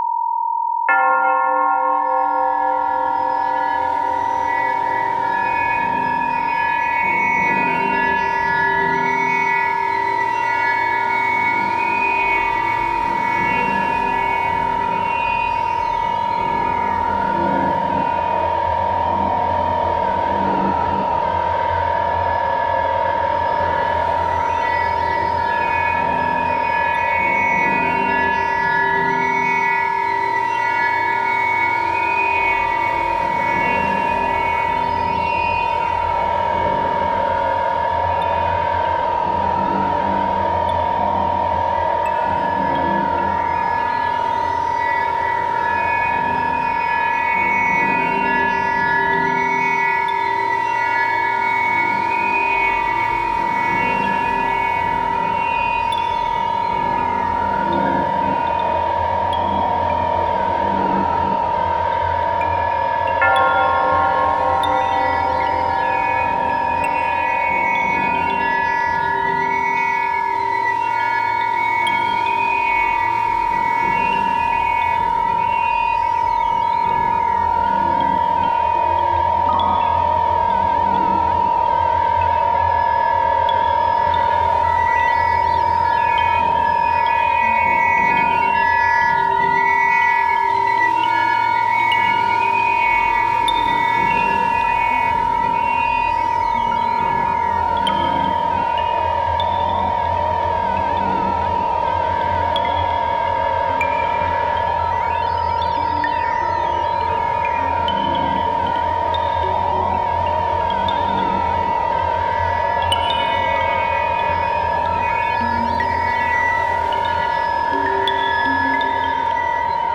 ある種のミニマル・アンビエント系の様相でありながら、総時間189分（3x3x3x3x7）をかけて、
更にこれら42曲は、特別な音響的デザインにより、ディープなバイノーラル・ビーツ体験をもたらす。
バイノーラル・ビーツの為の正弦波と、教会の鐘の音のサンプリングを除き、
総ての音はギター起源で録音されている。